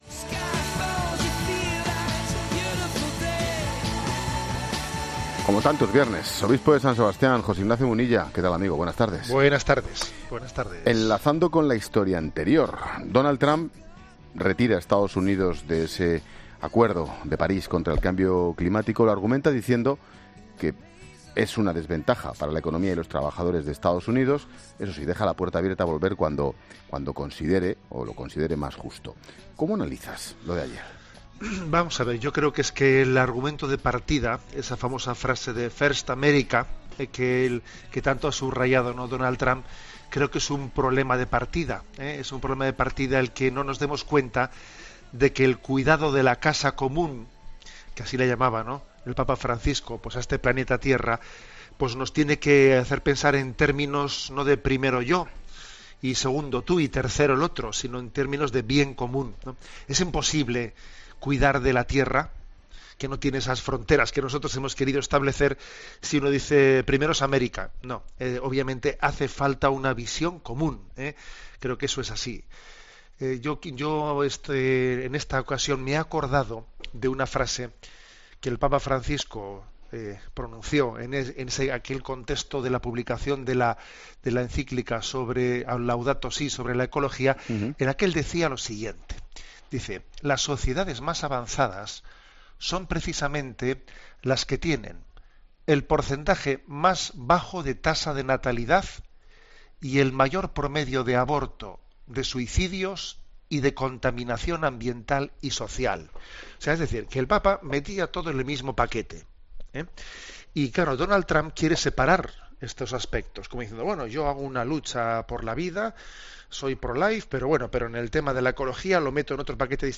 El obispo de San Sebastián, José Ignacio Munilla analiza la actualidad de la semana, como suele hacer muchos viernes en 'La Tarde' con Ángel Expósito.